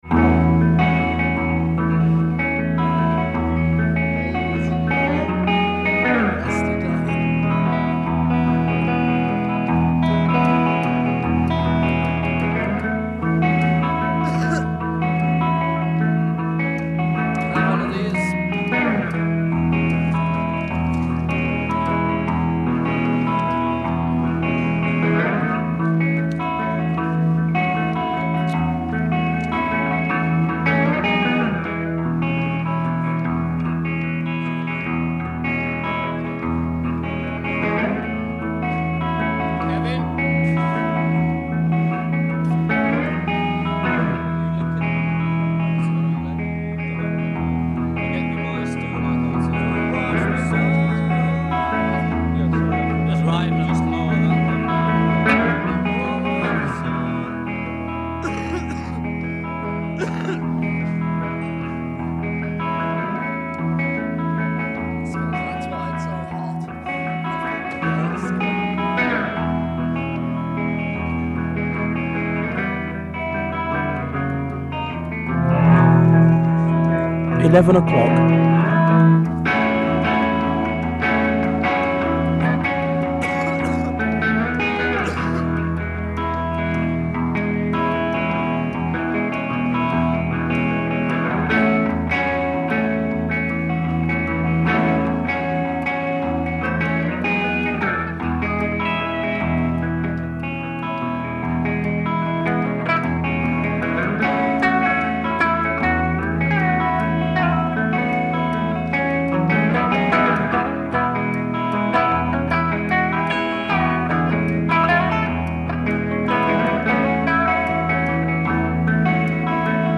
no vocals [Twickenham Studios